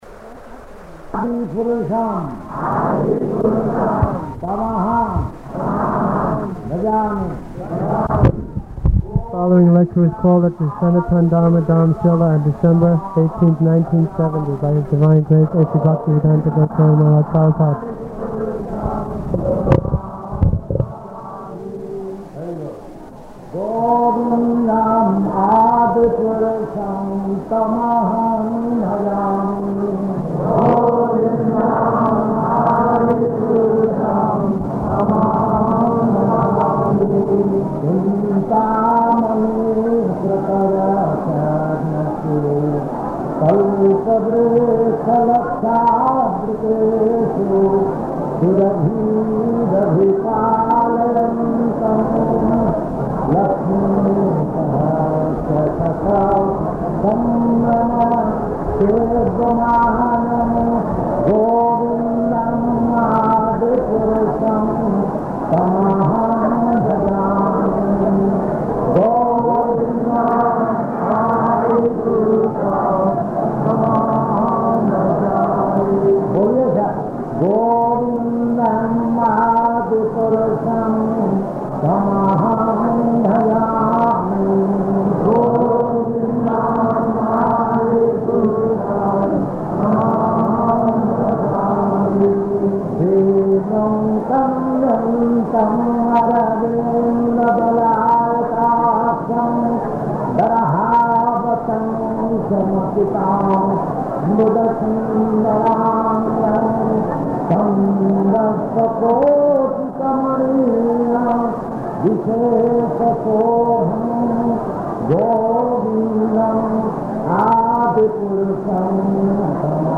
Lecture in Hindi
Lecture in Hindi --:-- --:-- Type: Lectures and Addresses Dated: December 18th 1970 Location: Surat Audio file: 701218LE-SURAT.mp3 Prabhupāda: [reciting prayers.]